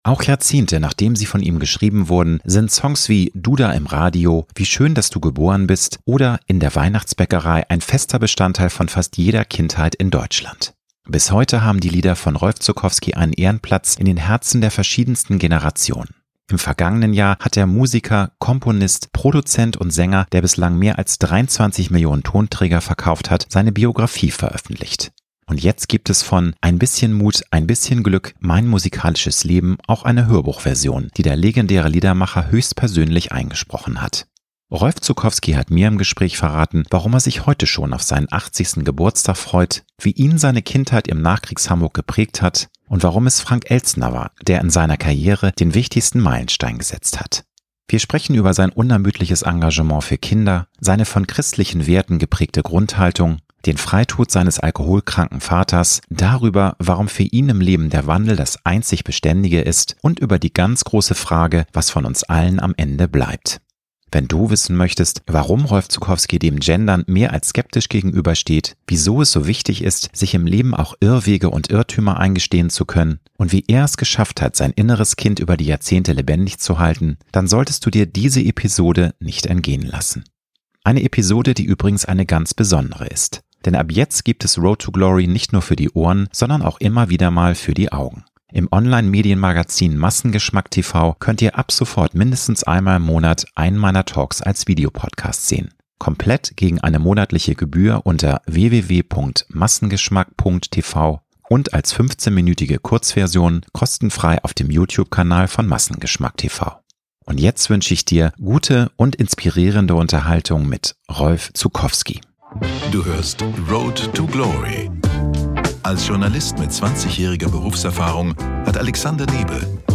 Rolf Zuckowski hat mir im Gespräch verraten, warum er sich heute schon auf seinen 80. Geburtstag freut, wie ihn seine Kindheit im Nachkriegs-Hamburg geprägt hat und warum es Frank Elstner war, der in seiner Karriere den wichtigsten Meilenstein gesetzt hat. Wir sprechen über sein unermüdliches Engagement für Kinder, seine von christlichen Werten geprägte Grundhaltung, den Freitod seines alkoholkranken Vaters; darüber, warum für ihn im Leben der Wandel das einzig Beständige ist und über die ganz große Fragen, was von uns allen am Ende bleibt.